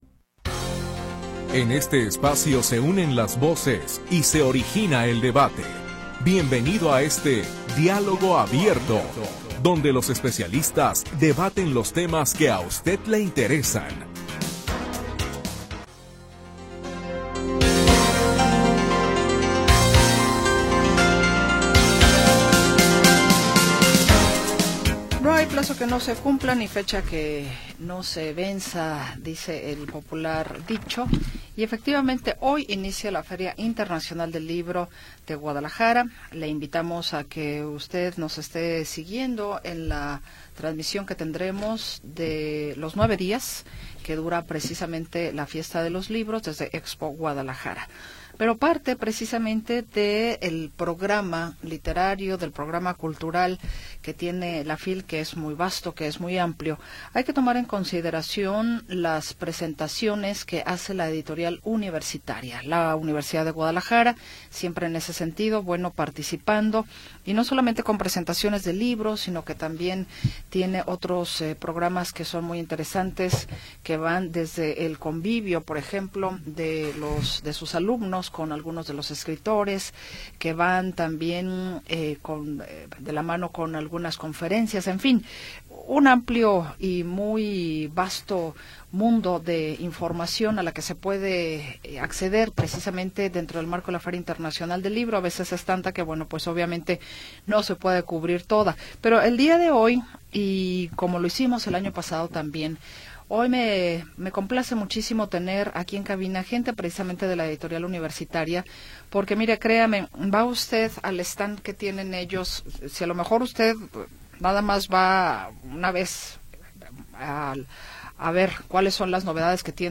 Temas de interés en debate